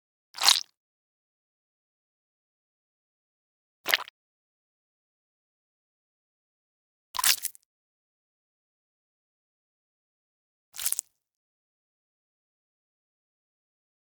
Flesh Small Squish Sound
horror